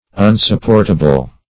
Unsupportable \Un`sup*port"a*ble\, a.